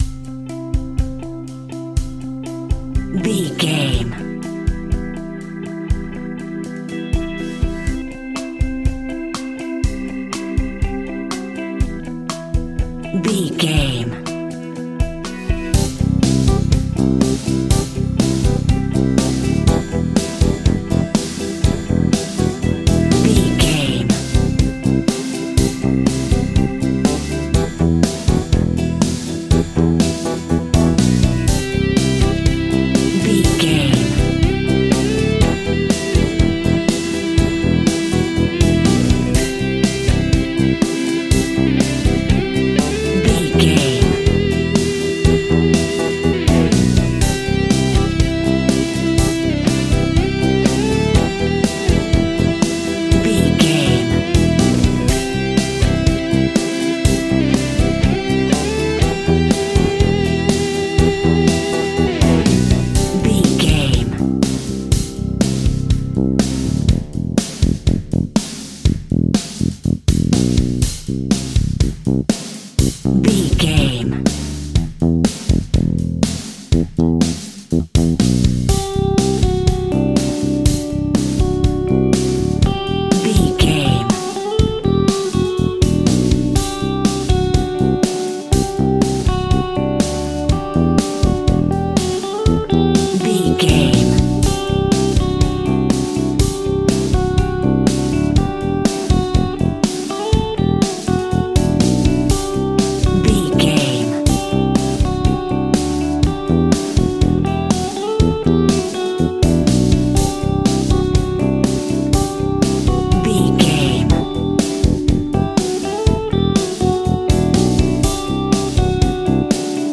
Pop Music for Advertising.
Ionian/Major
pop rock
indie pop
energetic
uplifting
upbeat
groovy
guitars
bass
drums
piano
organ